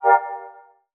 wake_word_triggered.wav